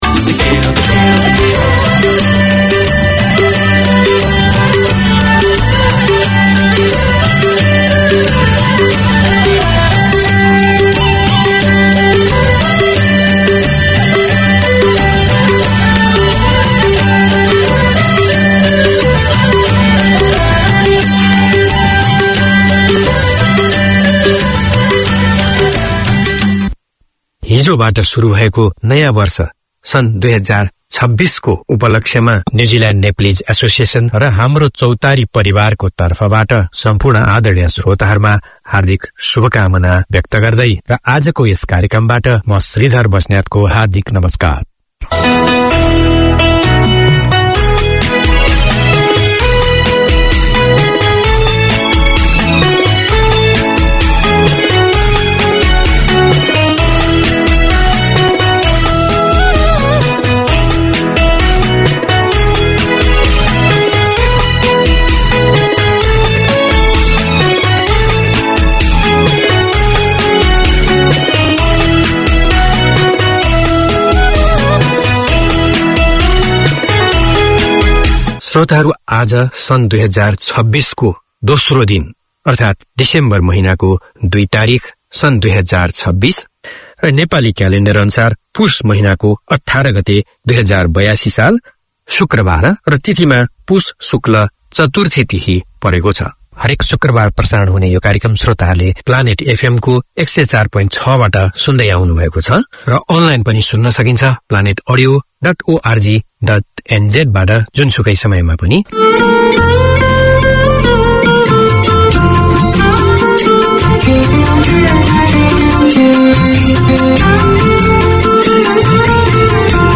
Community Access Radio in your language - available for download five minutes after broadcast.
Garden Planet tackles everything from seasonal gardening and garden maintenance, to problem-solving, troubleshooting, and what to plant and when. Tune in for garden goss, community notices and interviews with experts and enthusiasts on all things green or growing.